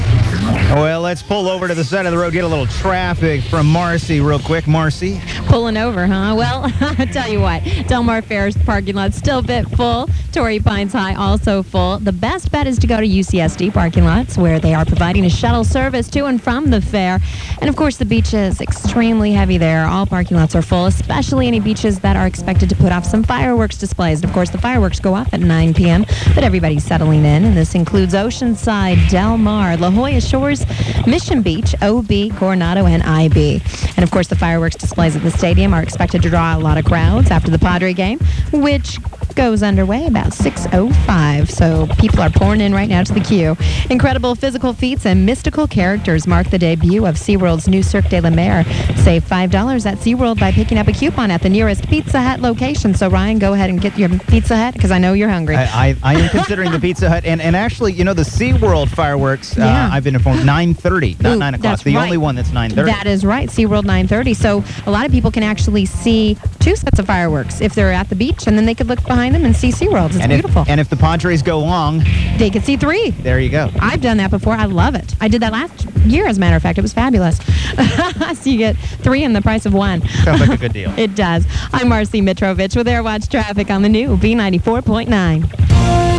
radio stations